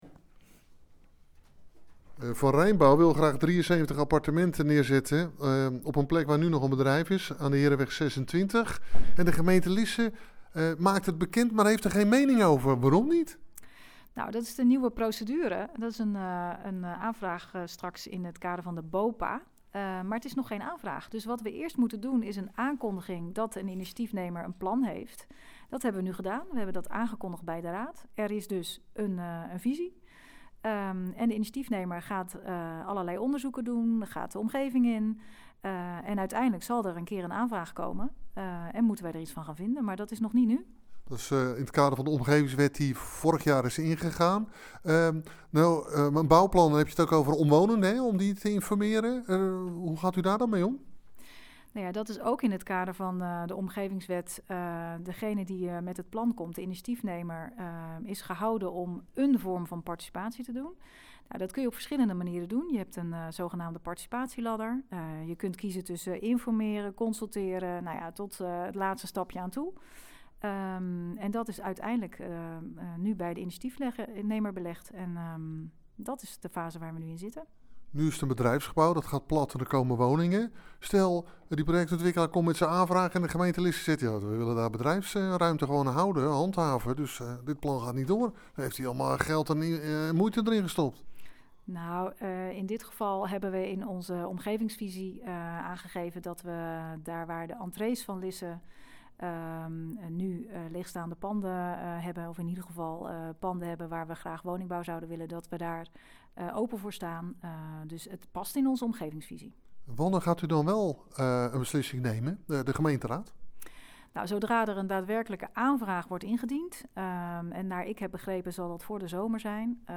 Hieronder het radio-interview: